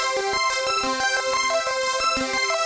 Index of /musicradar/shimmer-and-sparkle-samples/90bpm
SaS_Arp02_90-C.wav